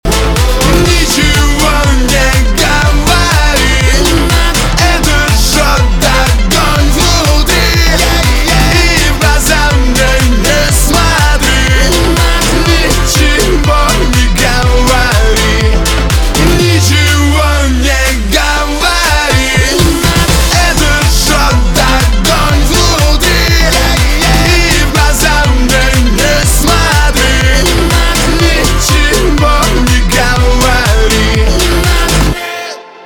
поп
cover , битовые , басы , качающие